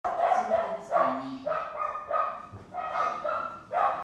Perros a las 2 am CARTAGO